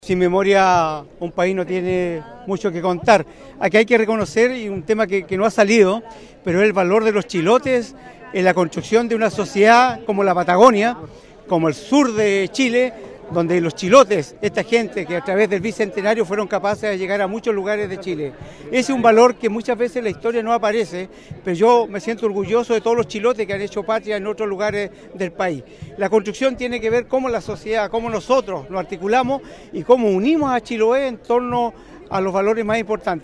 El Bicentenario de Chiloé se conmemoró en el Fuerte San Antonio de Ancud, región de Los Lagos, donde autoridades civiles y militares, representantes huilliches y distintas organizaciones se reunieron para recordar la historia del archipiélago y analizar sus desafíos actuales.
A su vez, el presidente de los Municipios de Chiloé y alcalde de Quinchao, René Garcés, destacó el rol de la memoria como base identitaria.